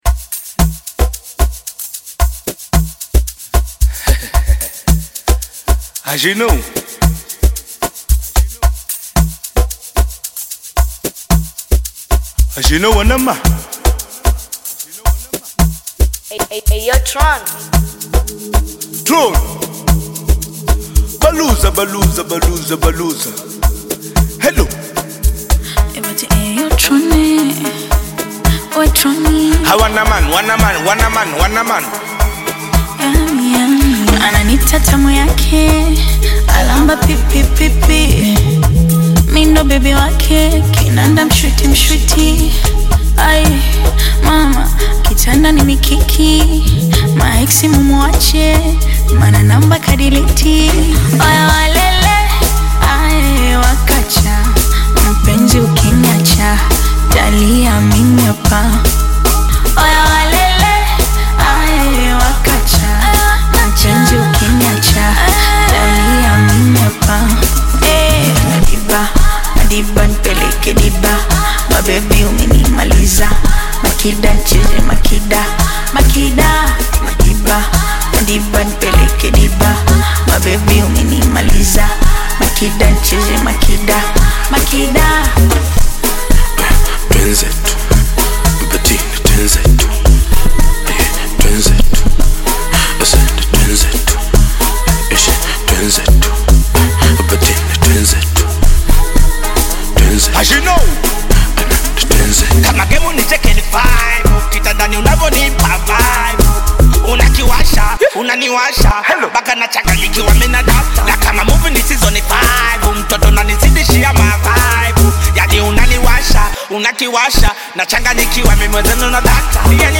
vibrant Afro-Beat/Bongo Flava collaboration
Genre: Amapiano